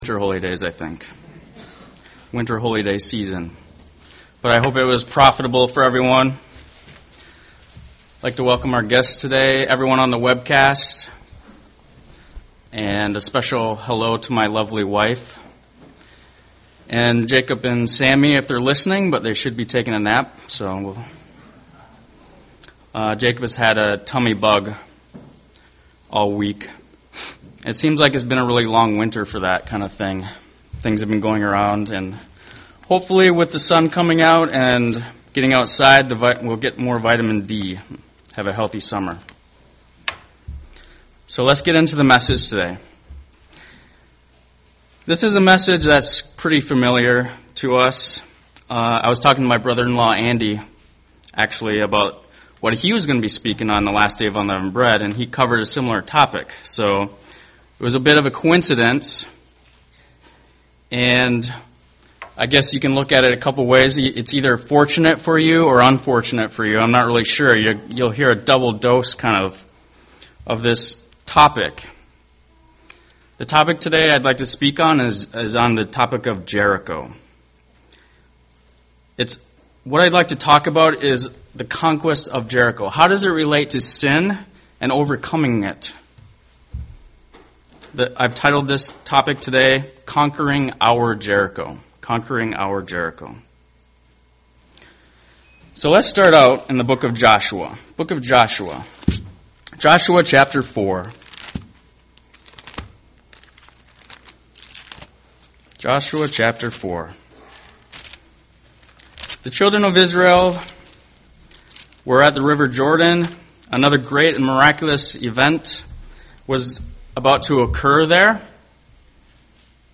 UCG Sermon Jericho Overcoming Sin Studying the bible?
Given in Twin Cities, MN